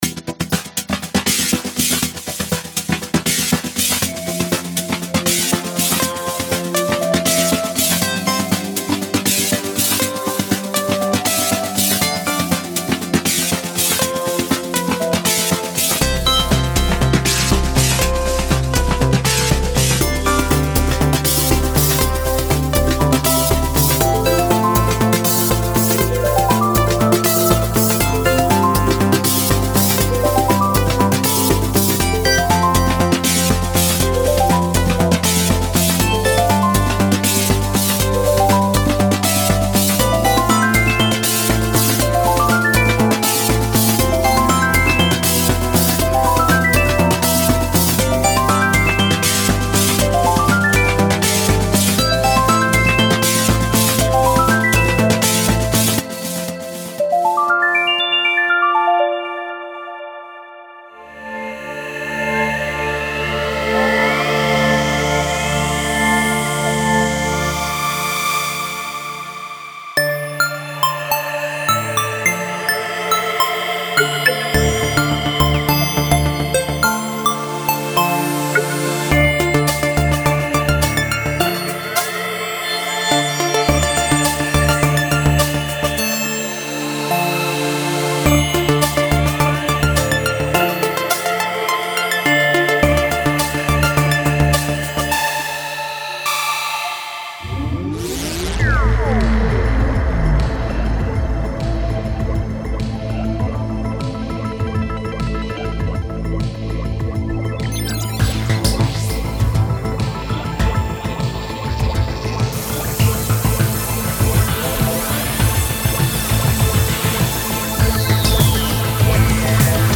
Creative Psychedelic: